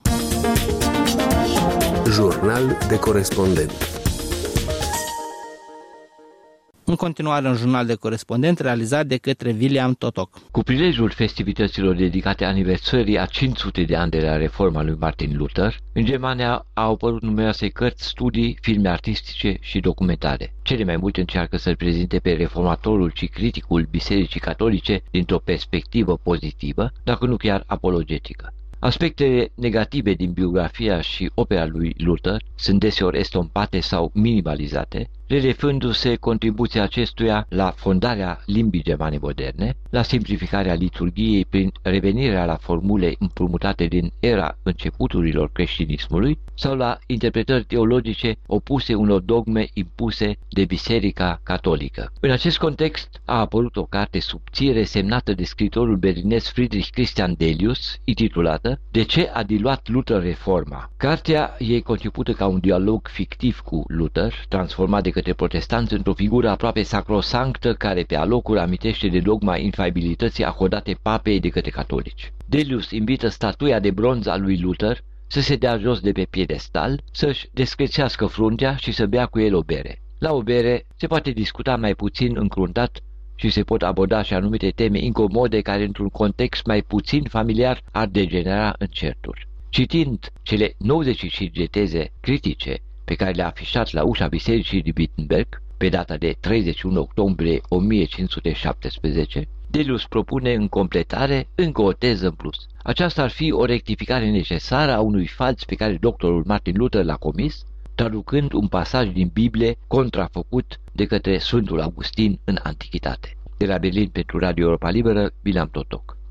Jurnal de Corespondent